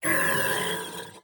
冷酷的哔哔声